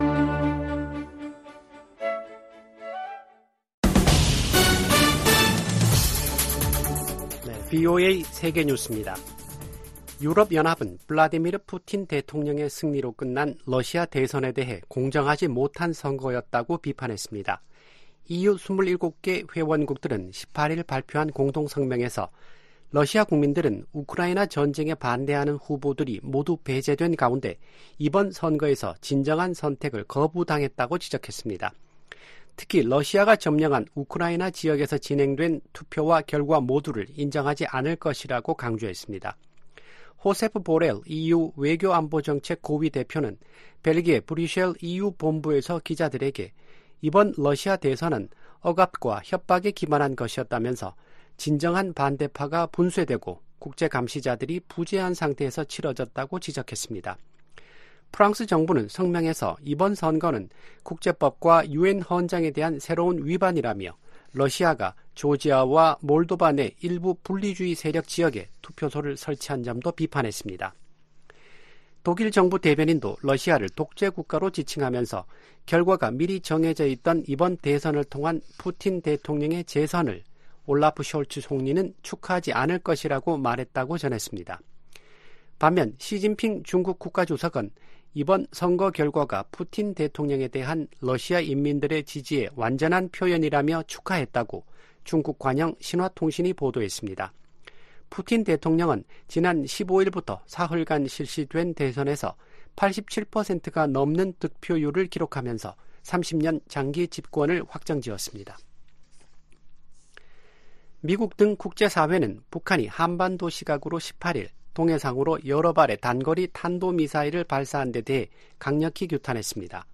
VOA 한국어 아침 뉴스 프로그램 '워싱턴 뉴스 광장' 2024년 3월 19일 방송입니다.